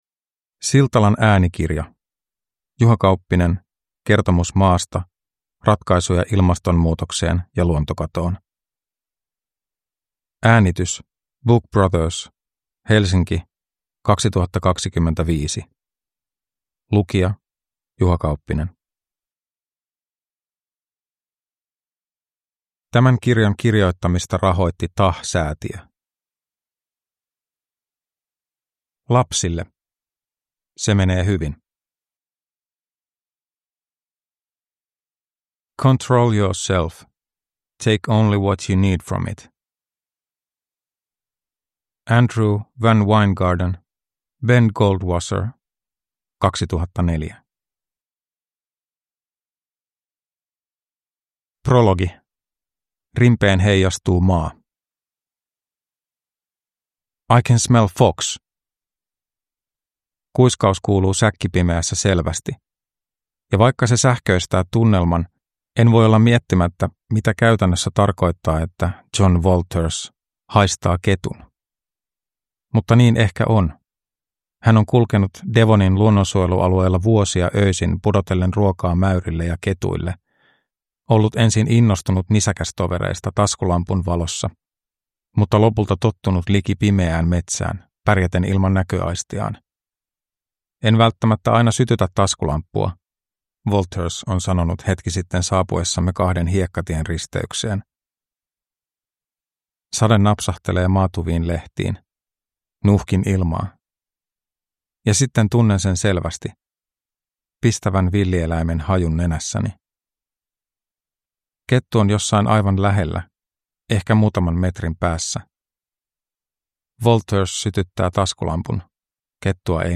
Kertomus Maasta – Ljudbok